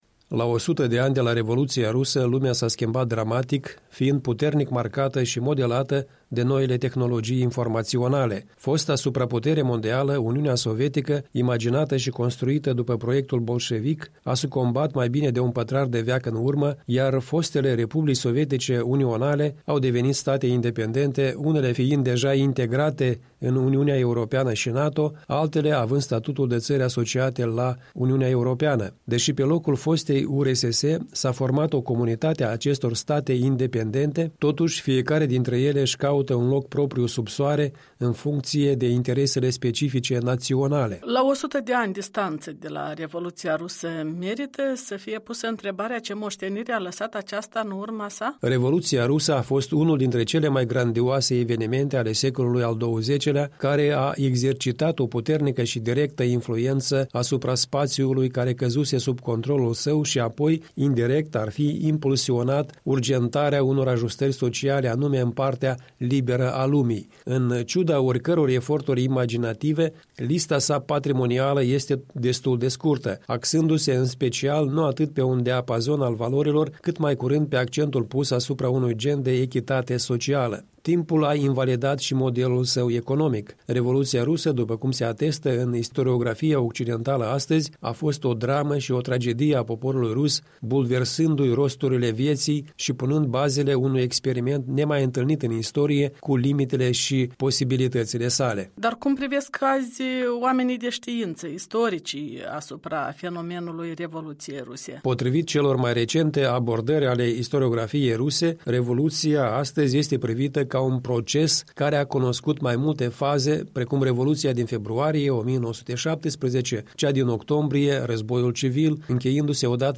Un punct de vedere săptămânal în dialog.